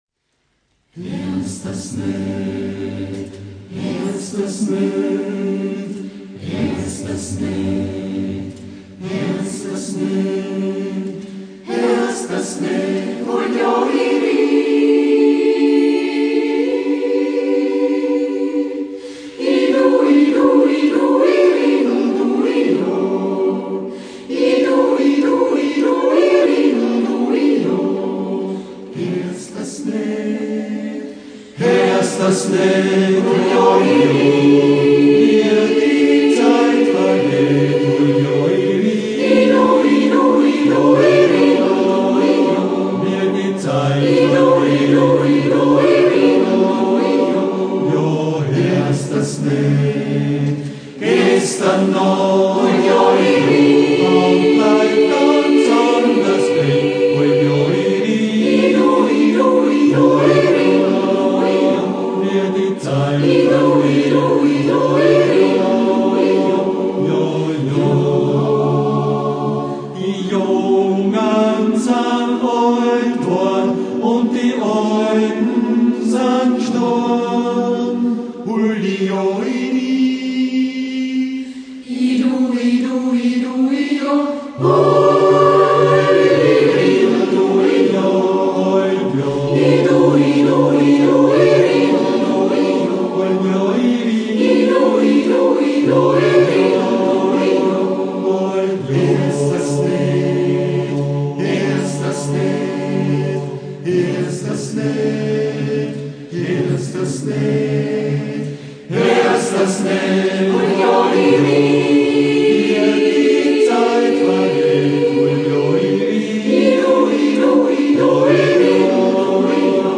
Produziert im Tonstudio